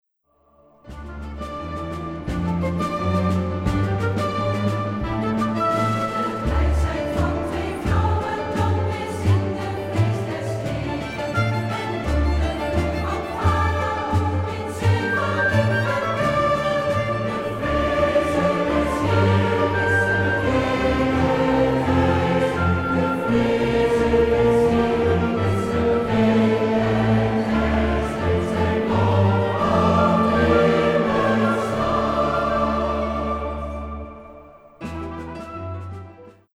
Oratorium over het leven van Mozes